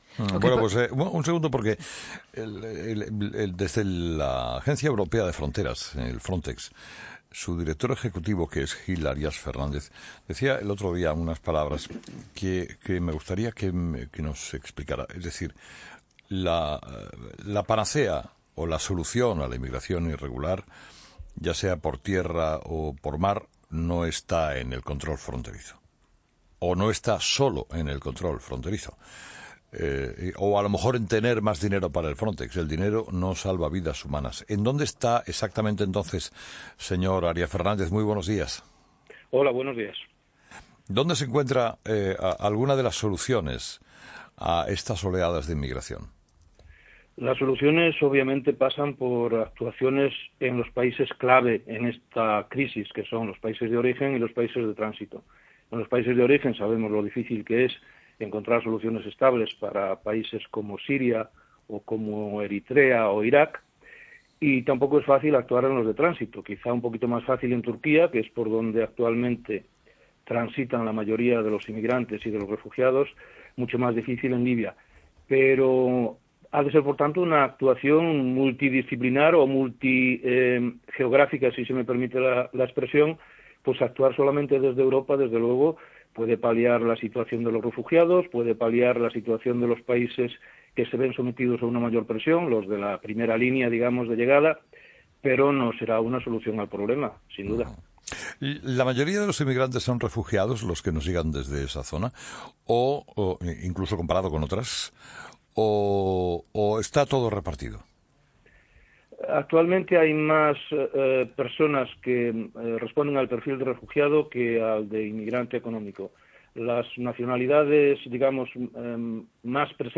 Entrevista a Gil Arias Fernández